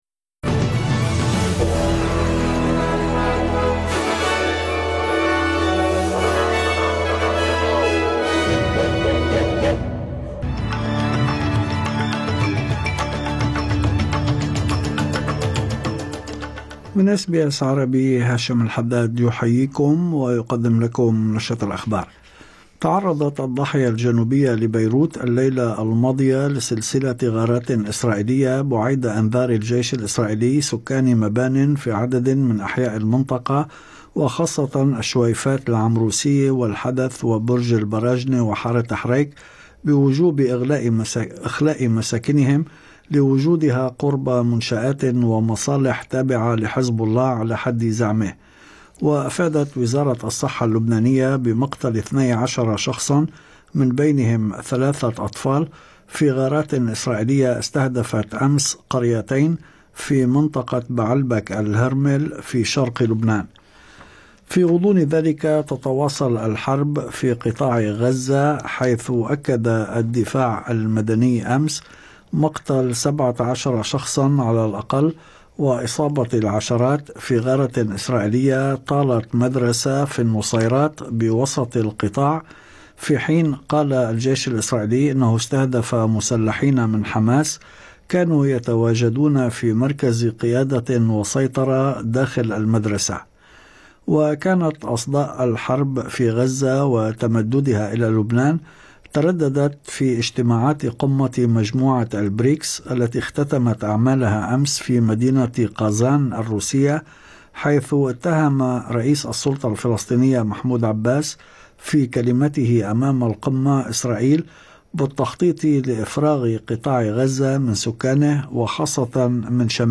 نشرة أخبار الظهيرة 25/10/2024